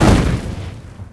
bombDet2.ogg